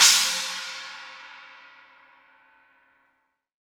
Q 16 China.WAV